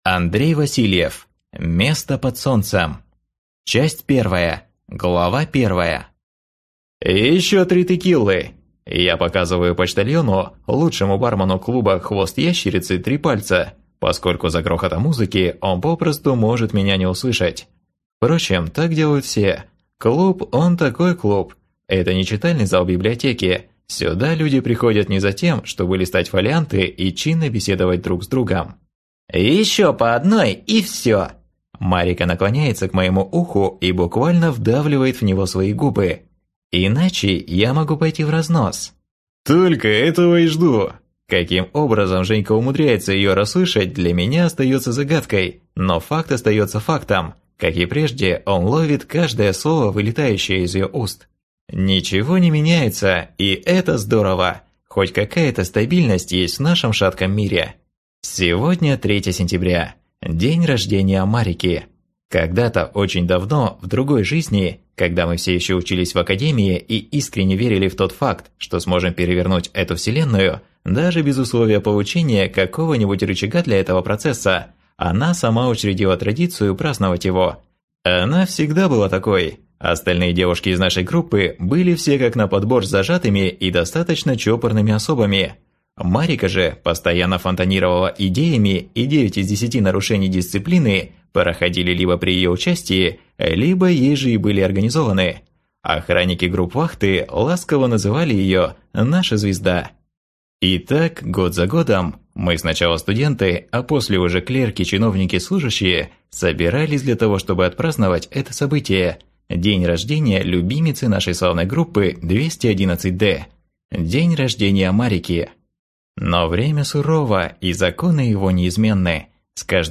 Аудиокнига Место под солнцем | Библиотека аудиокниг